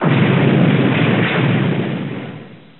FIGHT-Explosion+11
Tags: combat